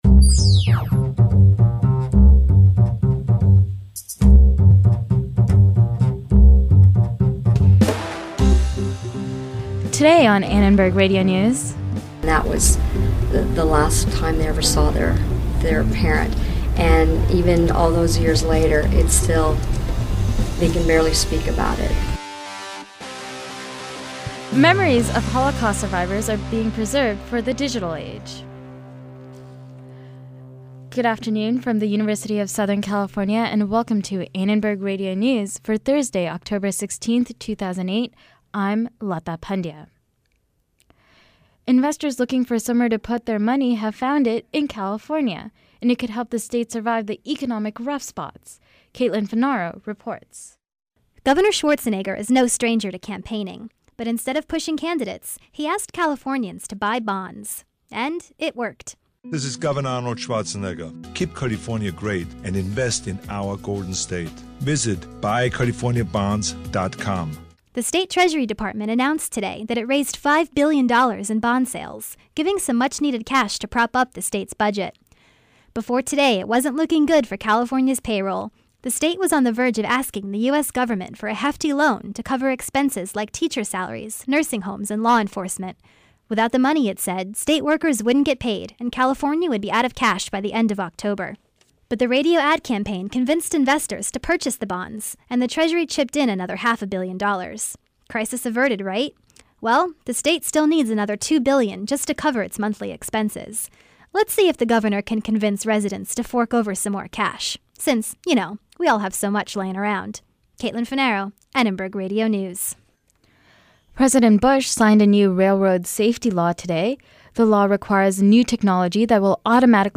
Live Guest